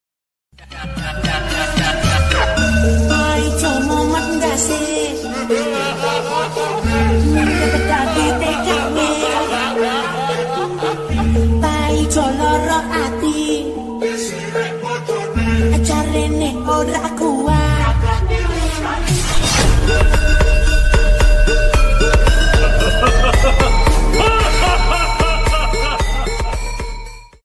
phonk_music
bassboosted